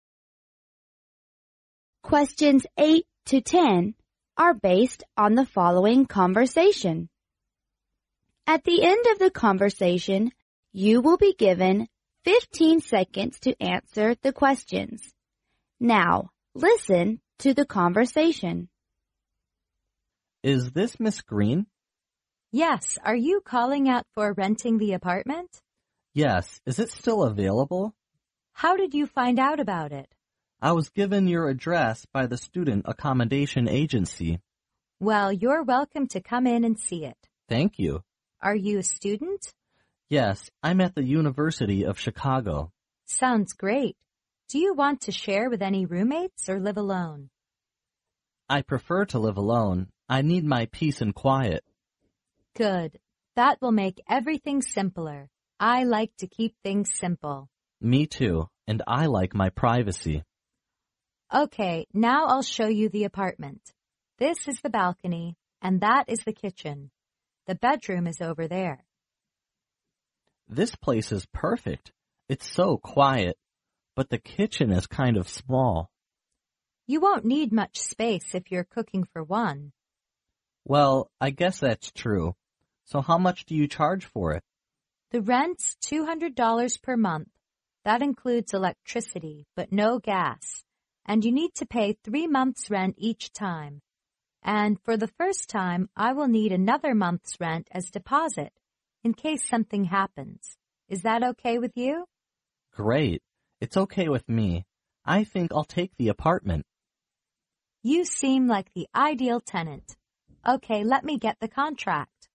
英语四级标准听力强化训练(MP3+文本) 第12期:对话(12)